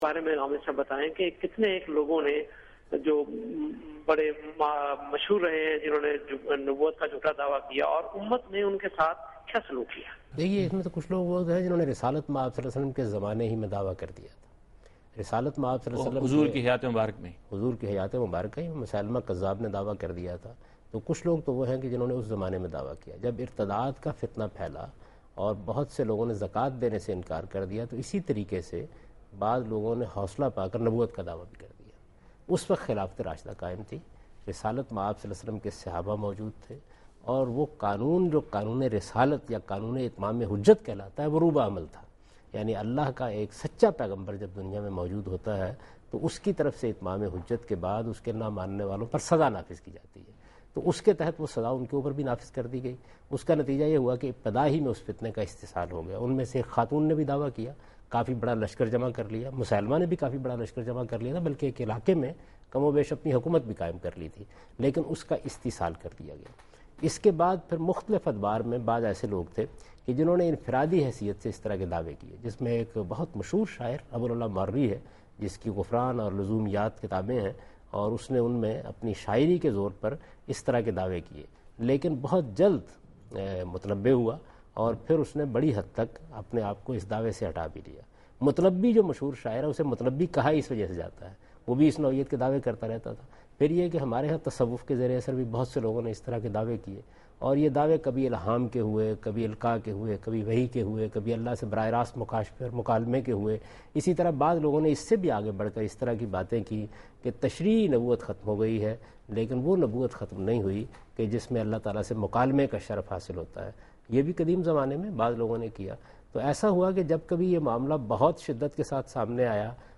Category: TV Programs / Dunya News / Deen-o-Daanish / Questions_Answers /
دنیا نیوز کے پروگرام دین و دانش میں جاوید احمد غامدی ”جھو ٹے نبی“ سے متعلق ایک سوال کا جواب دے رہے ہیں